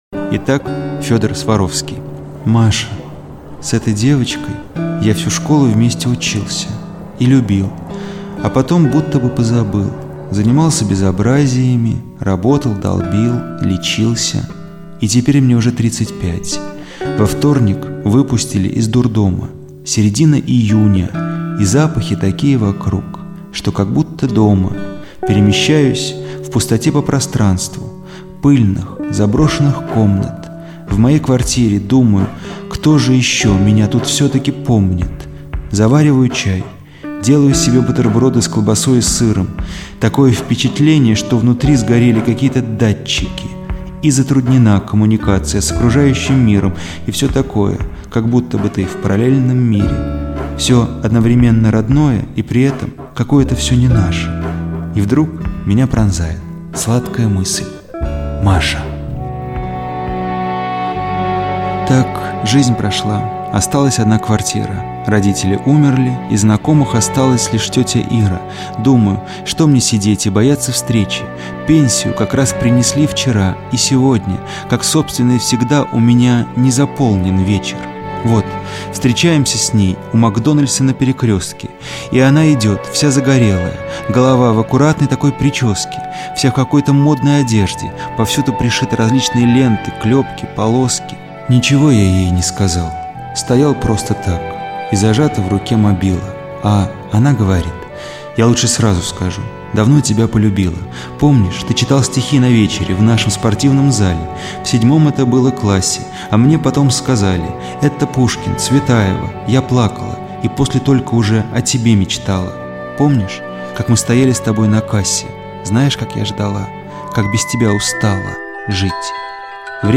Svarovskiy-D.Vodennikov-chitaet-Masha-stih-club-ru.mp3